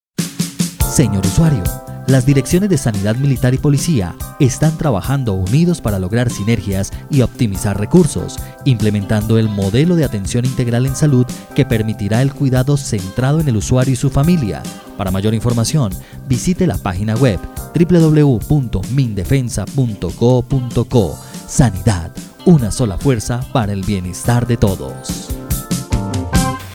Cuña Transformación de la Sanidad